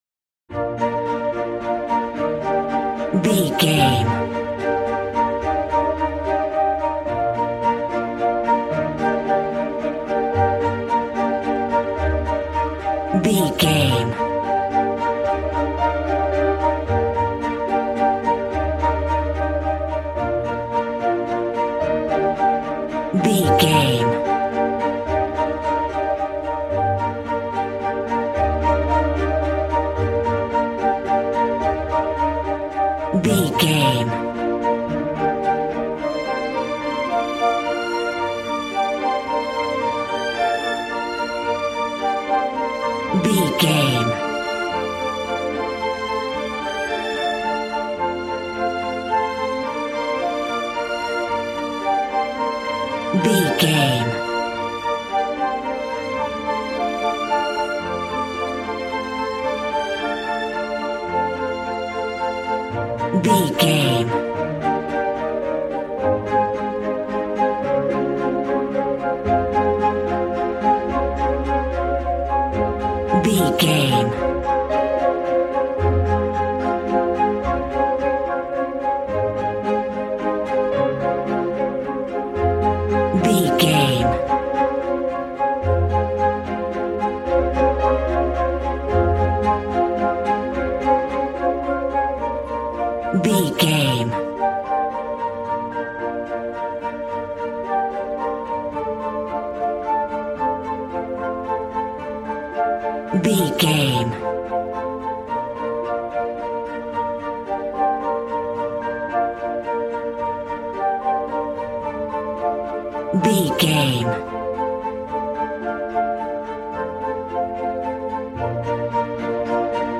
Regal and romantic, a classy piece of classical music.
Ionian/Major
B♭
regal
strings
violin
brass